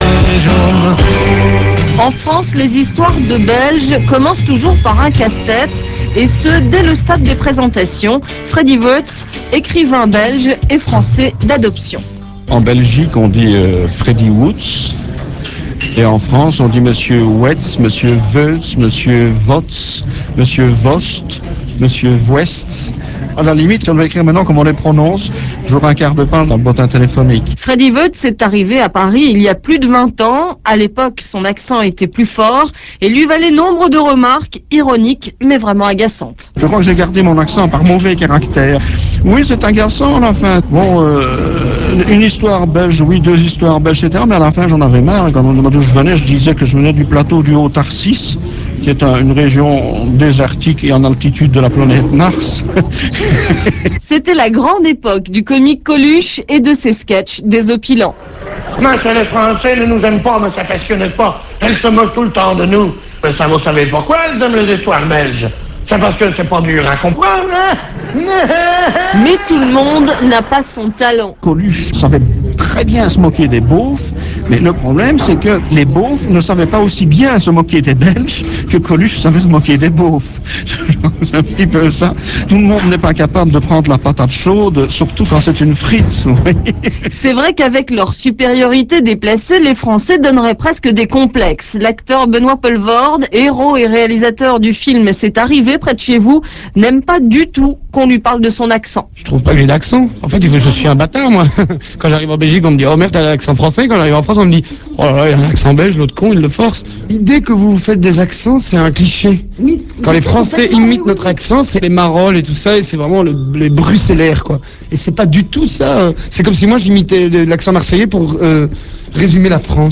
L'accent belge - un français bâtard ?
ACCENTBELGE.rm